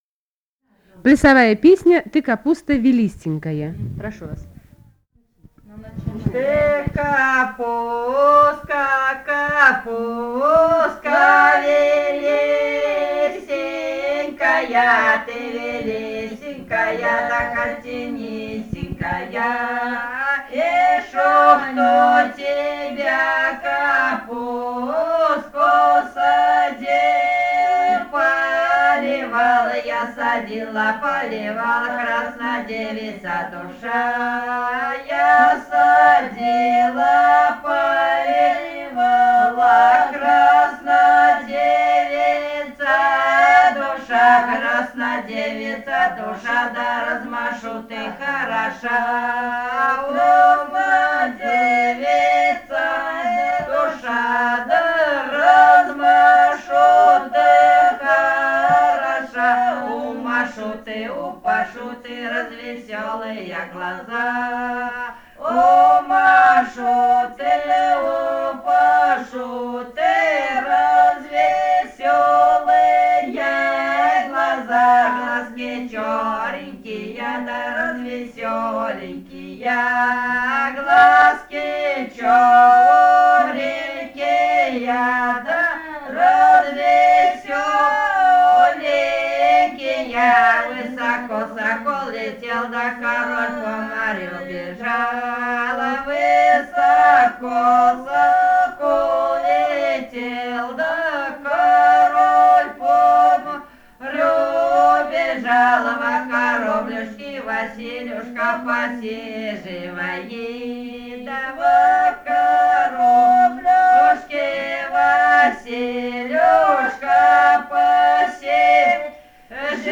Бурятия, с. Петропавловка Джидинского района, 1966 г. И0903-15